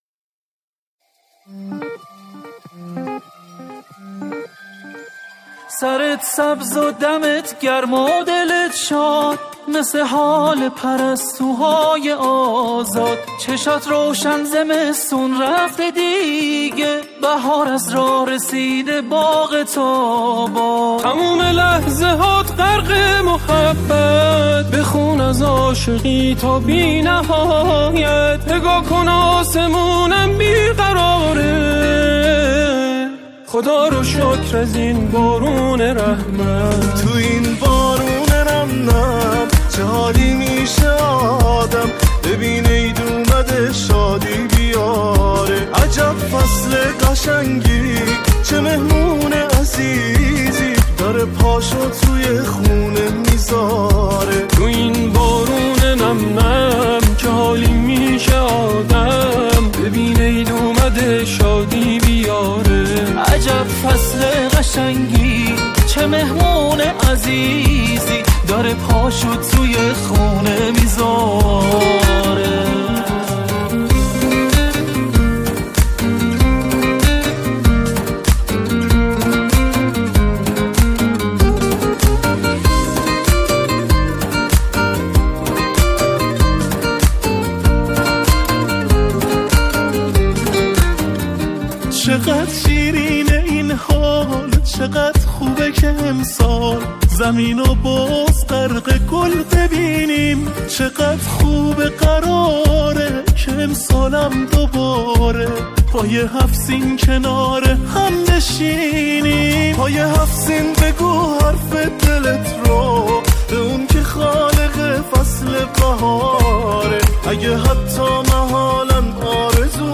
یک جمعخوانی با صدای چند خواننده